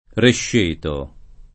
[ rešš % to ]